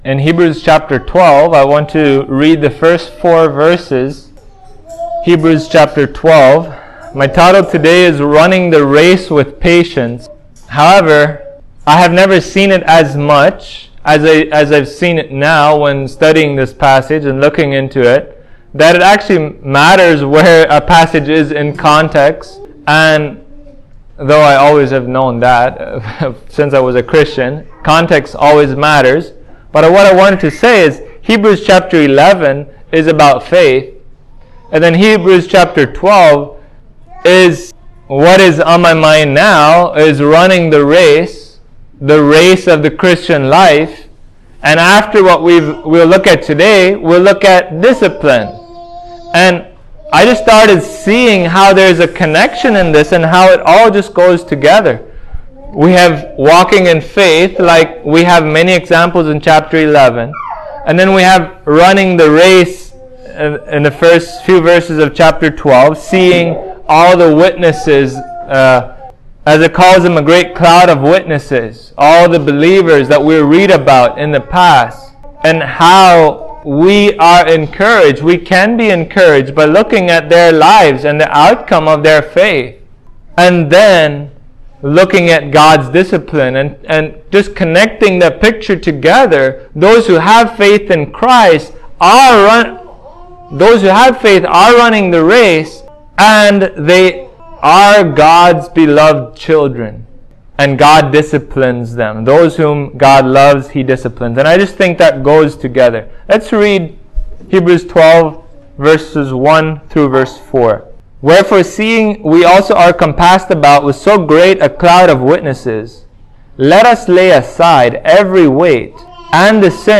Hebrews 12:1-4 Service Type: Sunday Morning Christ has promised to hold His people fast forever.